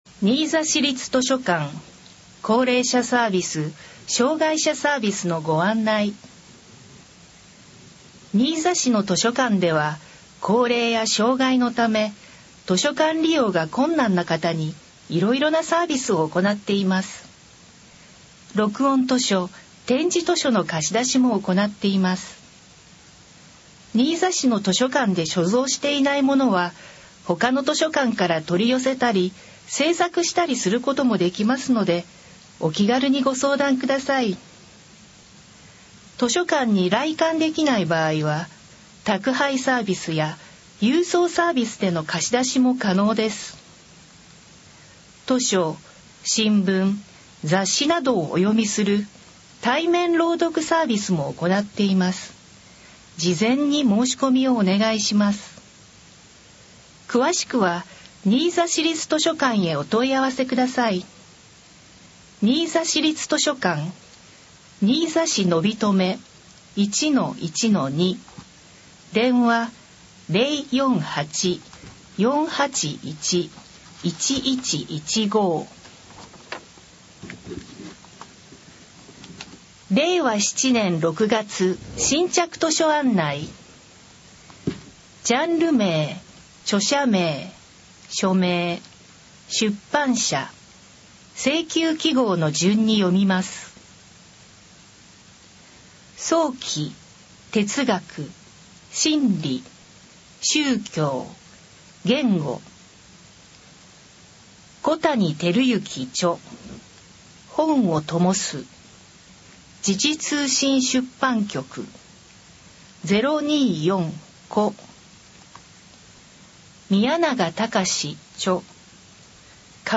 新着図書案内（音声版） － 新座市立図書館
新着図書案内（音声版）は、図書館朗読ボランティアグループ「こだま」の皆さんが、 「図書館だより」の新着図書案内を朗読し、CDに録音しています。